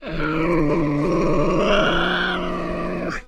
Звук рычания тасманийского дьявола